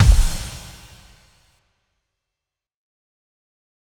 Big Drum Hit 02.wav